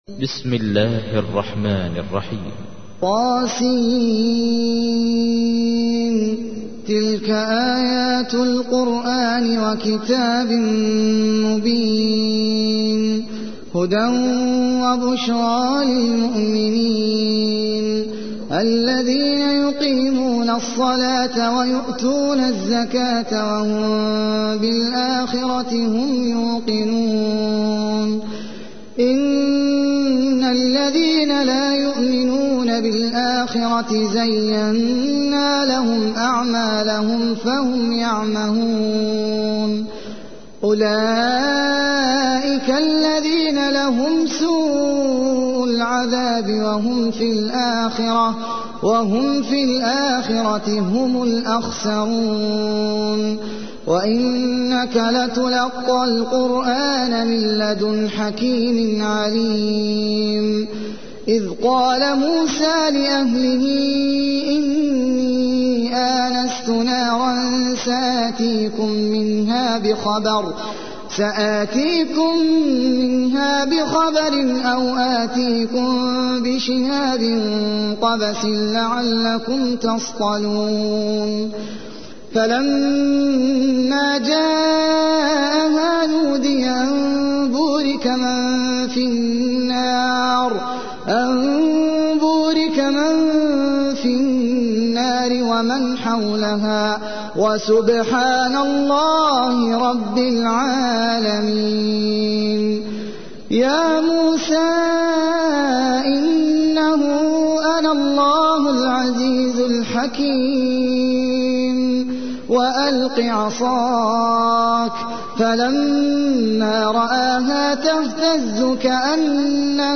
تحميل : 27. سورة النمل / القارئ احمد العجمي / القرآن الكريم / موقع يا حسين